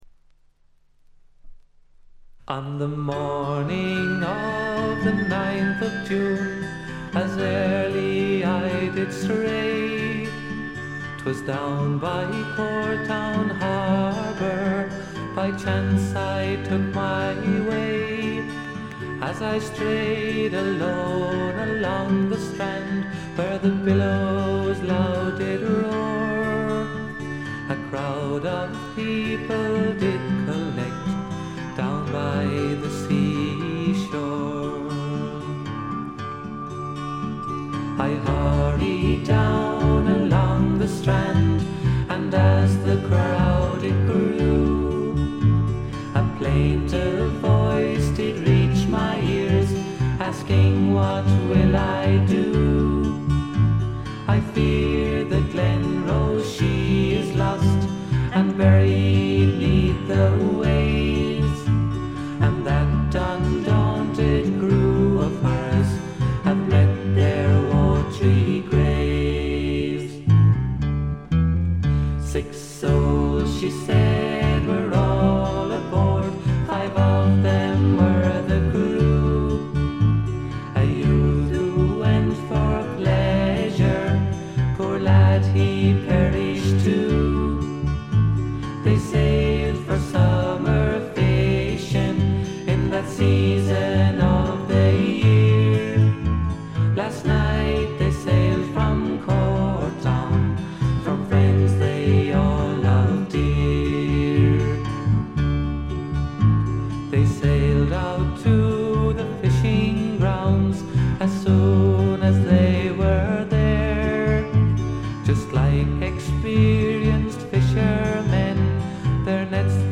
ほとんどノイズ感無し。
3人の美しいコーラスが聴きどころですが楽器演奏も基本的に3人でこなします。
試聴曲は現品からの取り込み音源です。